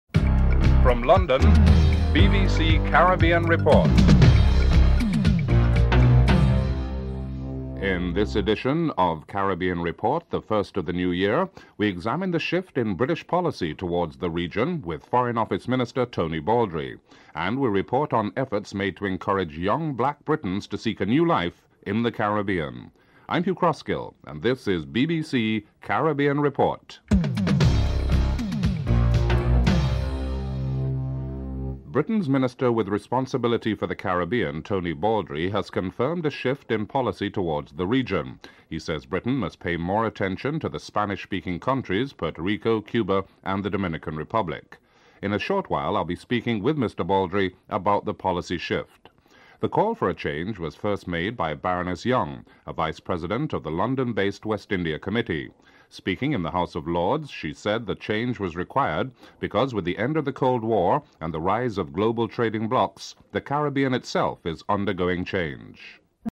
5. Tony Baldry reports on the British attitude towards the US embargo on Cuba (08:55-10:22)
8. Interview with various participants (10:52-12:04)
11. Interview with Marcia Roberts, Deputy High Commissioner of Jamaica (13:08-13:46)